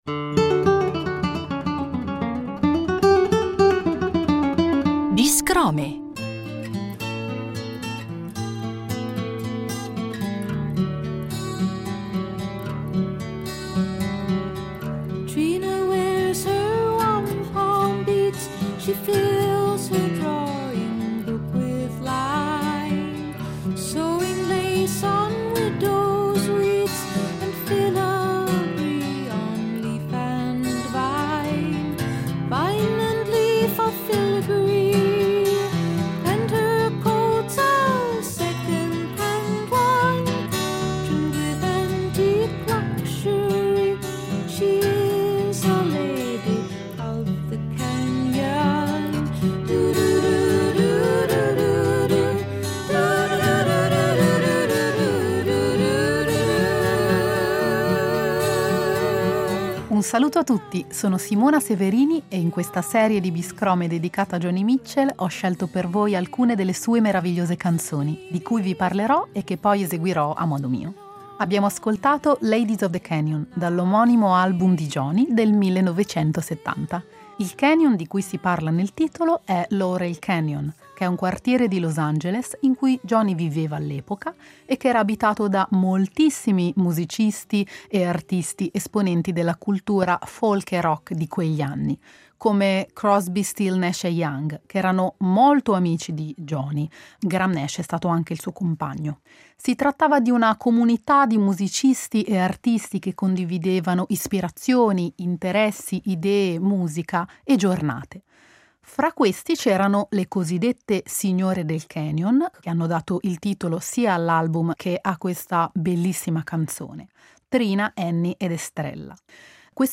ogni puntata è impreziosita dalle sue interpretazioni da sola, con la chitarra